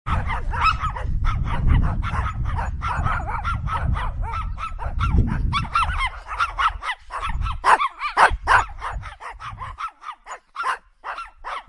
Chihuahua Barking In The Mountains Botón de Sonido